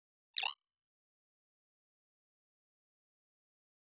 Futurisitc UI Sound 18.wav